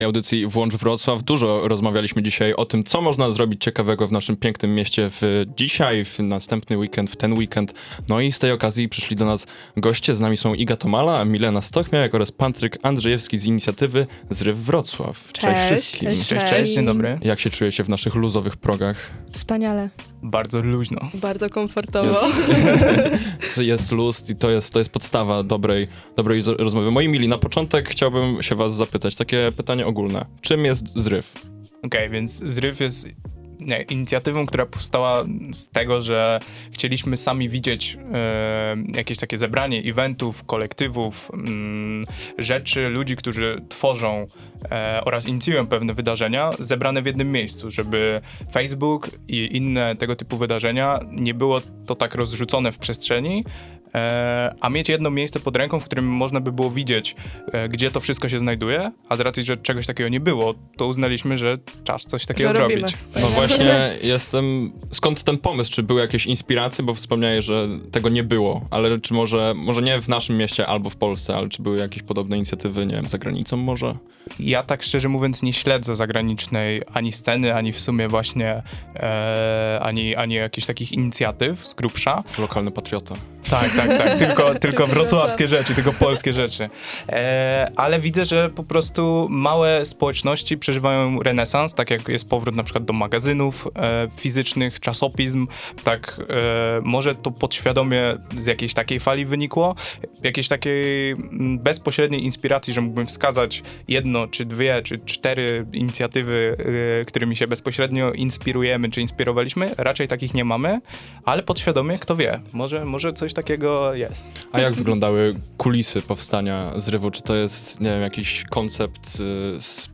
Z kontrą wychodzą pomysłodawcy całego zamieszania, którzy odwiedzili nasze studio w trakcie piątkowego wydania audycji „Włącz Wrocław”: